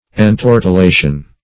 Search Result for " entortilation" : The Collaborative International Dictionary of English v.0.48: Entortilation \En*tor`ti*la"tion\, n. [F. entortiller to twist; pref. en- (L. in) + tortiller to twist.] A turning into a circle; round figures.